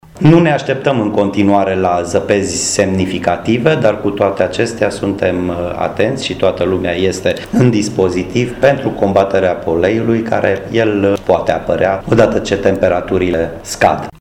Miklos Gantz, administratorul public al Municipiului Braşov: